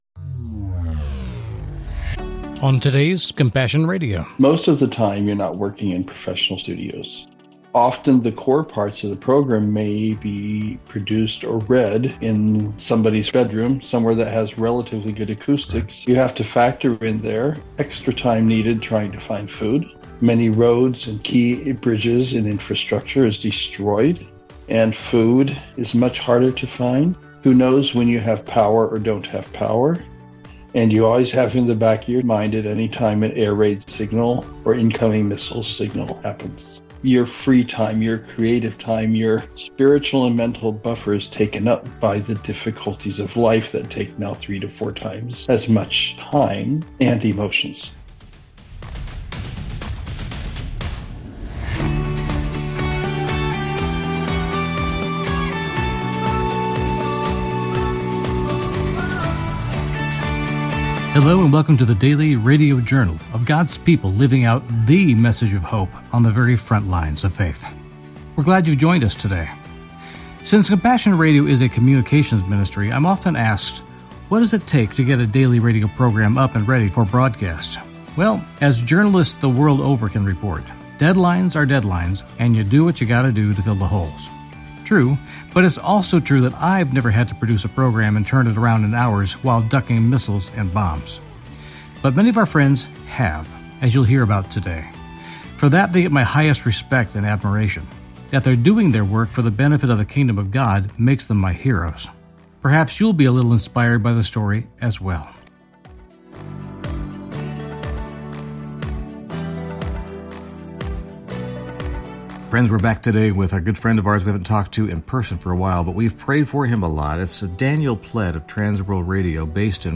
Genre: Christian News Teaching and Talk.